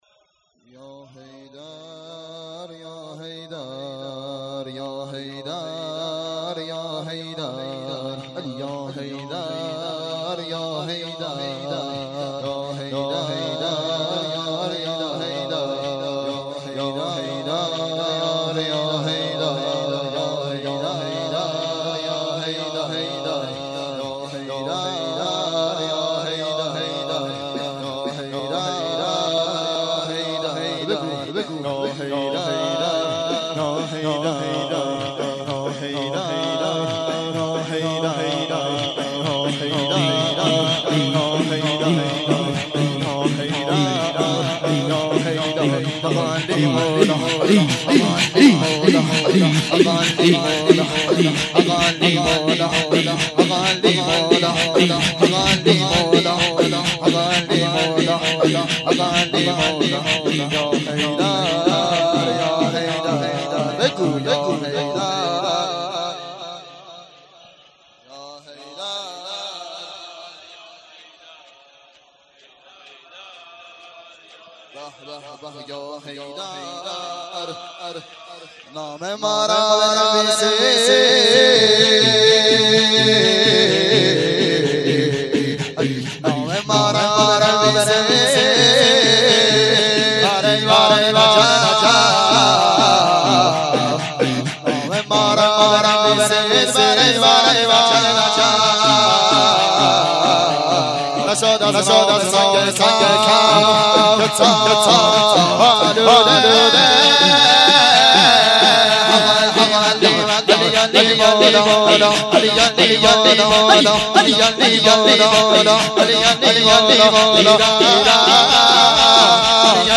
مراسم شهادت حضرت فاطمه زهرا سلام الله علیها آبان ۱۴۰۳